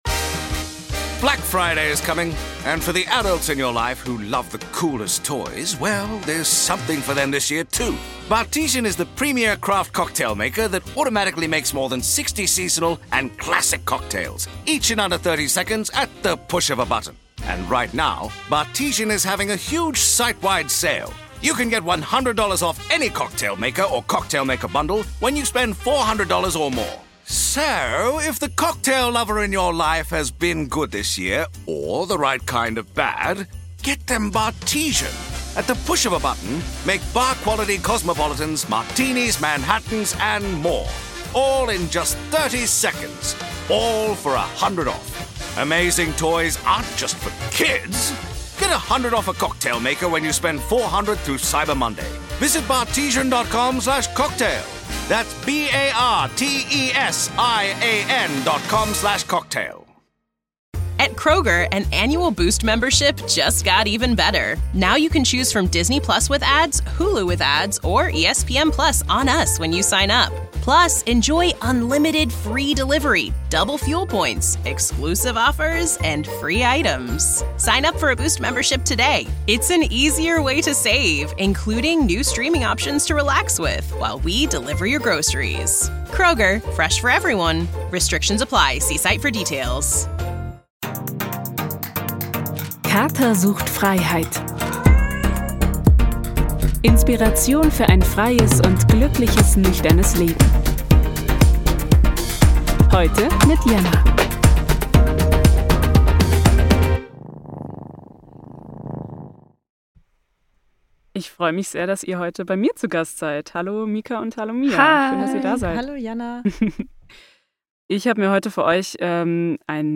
Beschreibung vor 1 Jahr Die beiden coolen Ladys vom Sodaklub und ich haben uns endlich mal zum Quatschen getroffen!